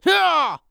CK长声05.wav
CK长声05.wav 0:00.00 0:00.71 CK长声05.wav WAV · 61 KB · 單聲道 (1ch) 下载文件 本站所有音效均采用 CC0 授权 ，可免费用于商业与个人项目，无需署名。
人声采集素材/男2刺客型/CK长声05.wav